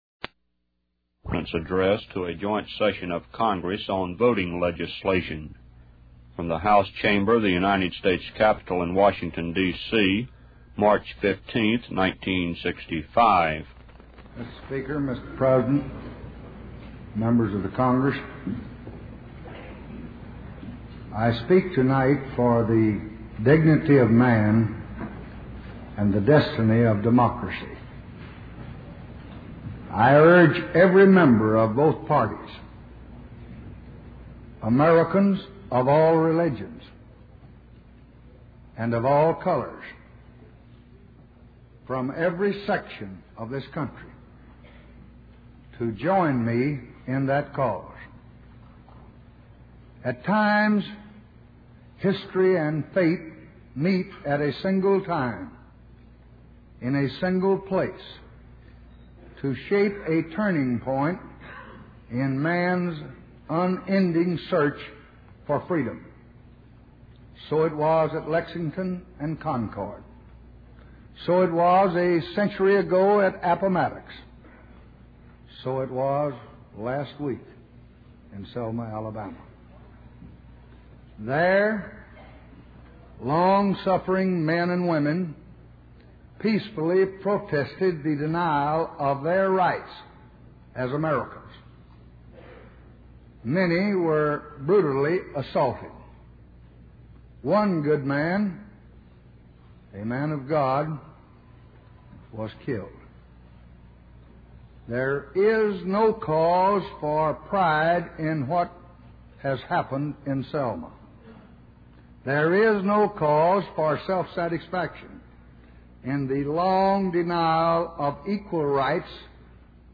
Joint Session of Congress Address on Voting Legislation